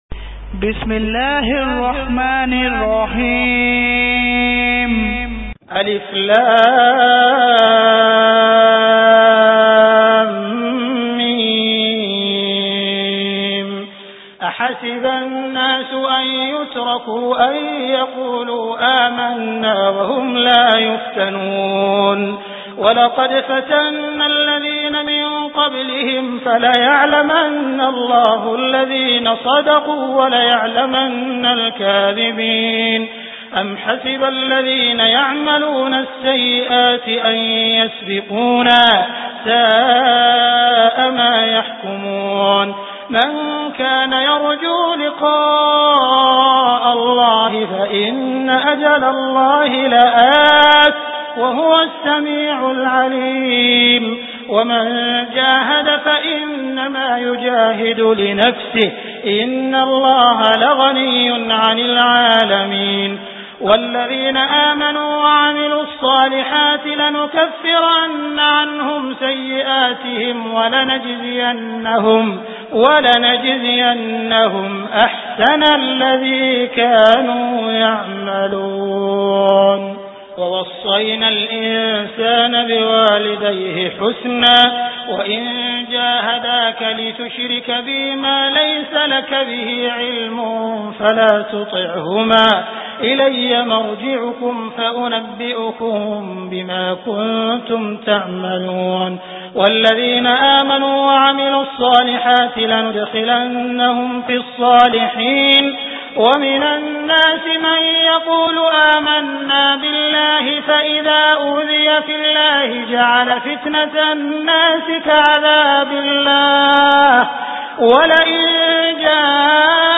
Surah Al Ankabut Beautiful Recitation MP3 Download By Abdul Rahman Al Sudais in best audio quality.